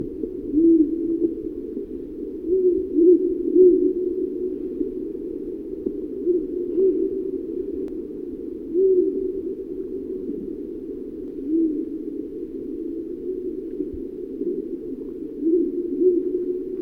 Alle Fotos, Filme und Vogelstimmen sind von mir selbst aufgenommen.
Habichtskauzmit Ton
Strix uralensis
habichtskauz.mp3